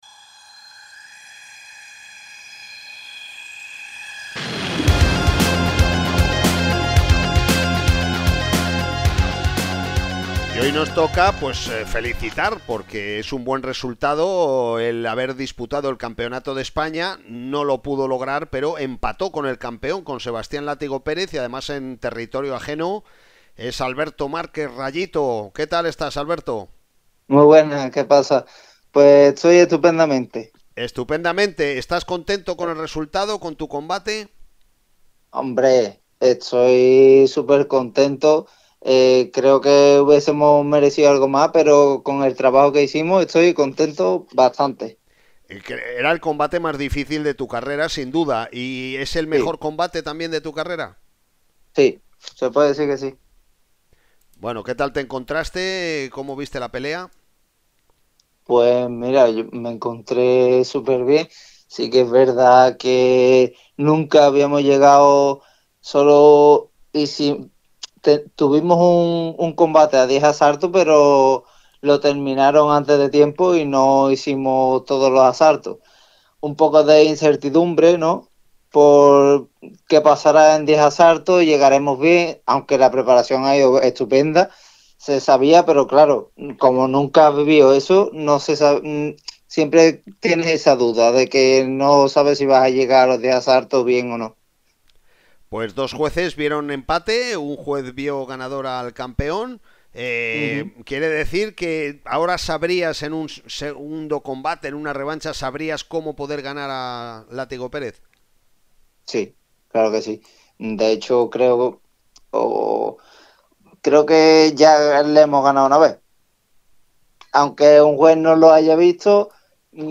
Seguimos con la sección semanal de los jueves llamada «Las Voces del Boxeo», en la que los principales personajes de la actualidad de nuestro pugilismo son entrevistados radiofónicamente